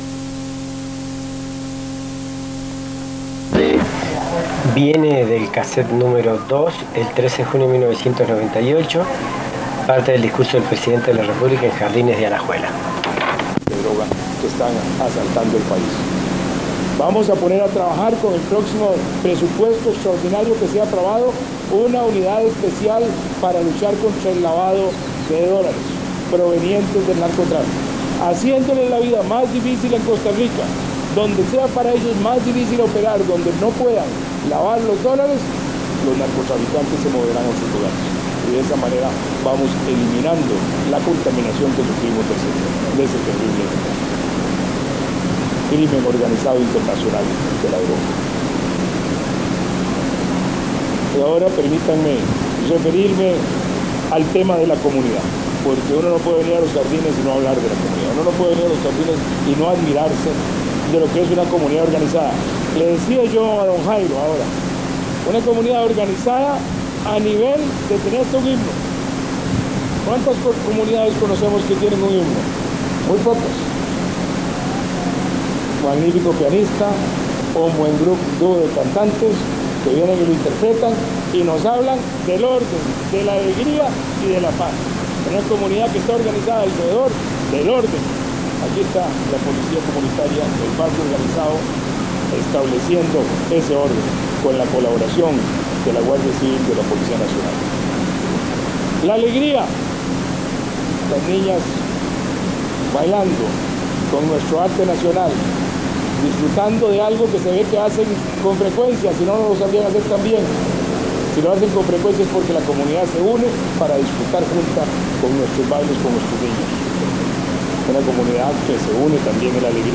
Notas: Casette de audio